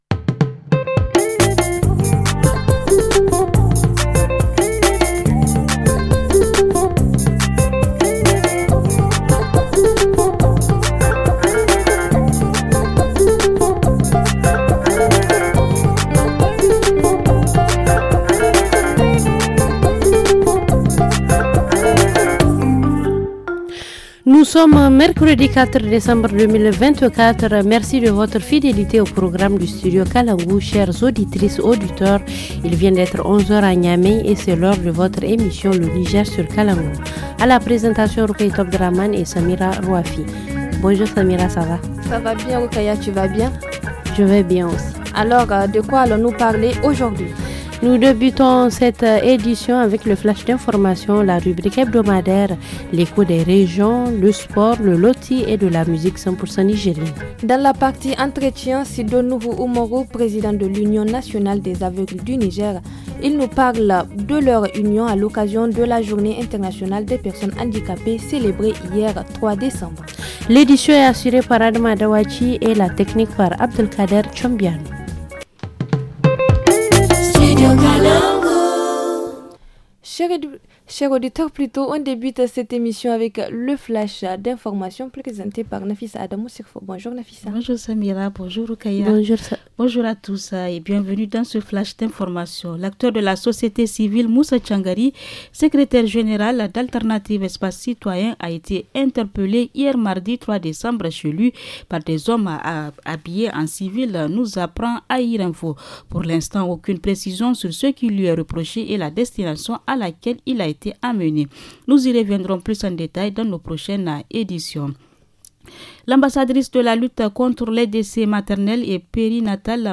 Dans la rubrique hebdomadaire, la jeunesse de Kara kara qui se mobilise pour la protection de l’environnement. En reportage région, zoom sur l’impact de l’assouplissement des contrôles douaniers entre le Bénin et le Niger à Gaya.